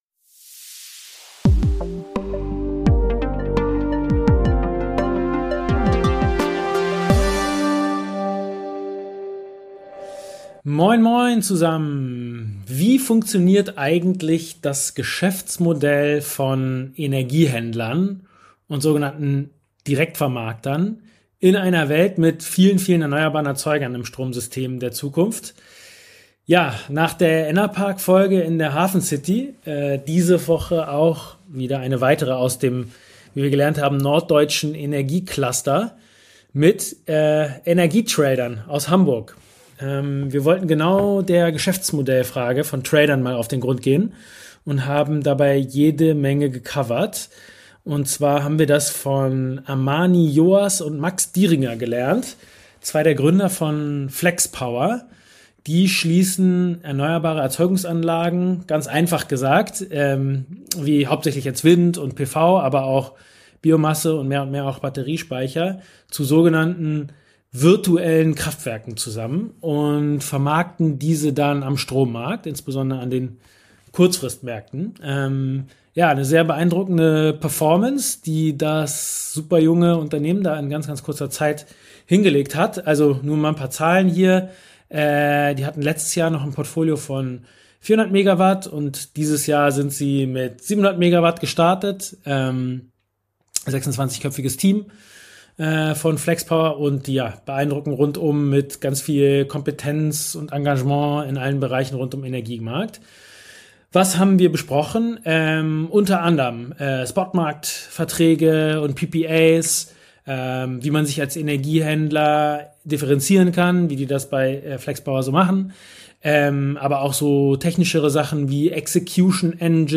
Ein weiterer Aspekt des Interviews befasst sich mit den Entwicklungen im Energiebereich und den Auswirkungen auf den Markt, insbesondere im Zusammenhang mit Power Match als alternative Lösung zur traditionellen Energiebeschaffung. Es wird betont, dass Baseload als Standardprodukt am Markt veraltet und alternative Hedging-Möglichkeiten bevorzugt werden sollten.